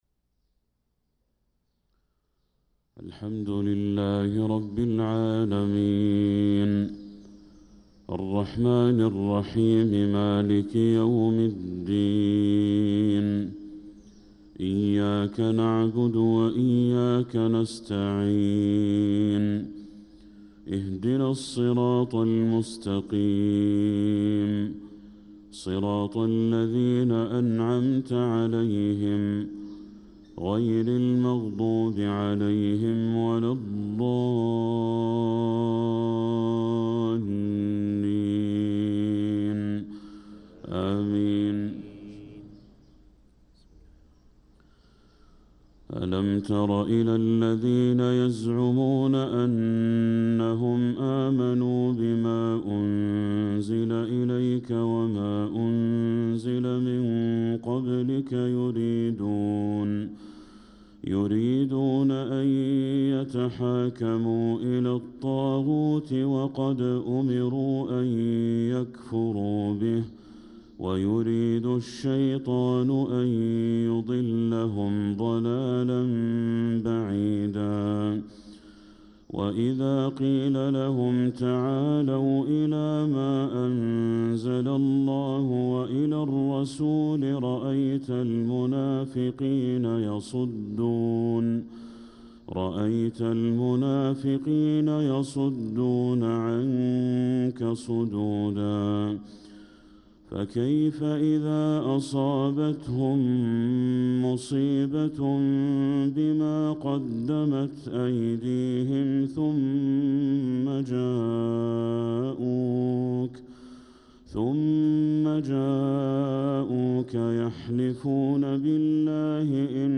صلاة الفجر للقارئ بدر التركي 29 ربيع الآخر 1446 هـ
تِلَاوَات الْحَرَمَيْن .